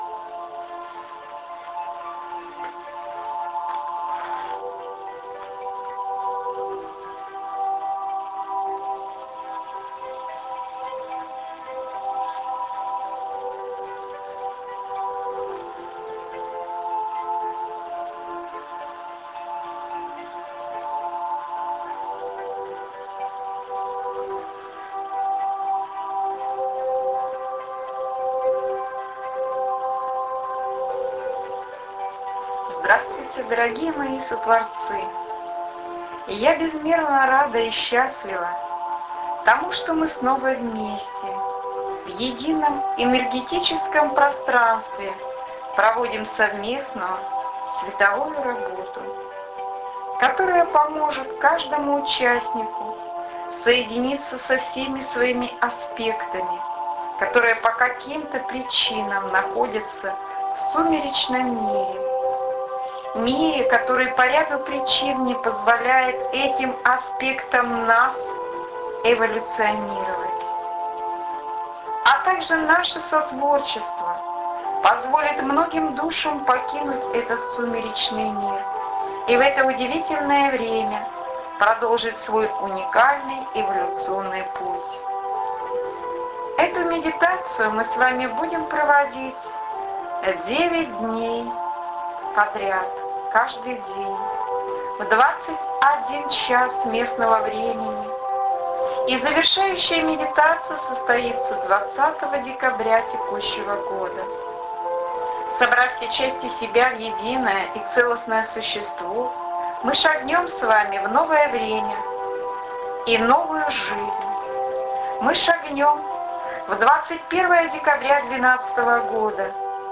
12.12.2012 аудио-медитация Здравствуйте дорогие мои Сотворцы.